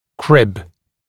[krɪb][криб]съемный фиксатор (для ортодонтических приспособлений)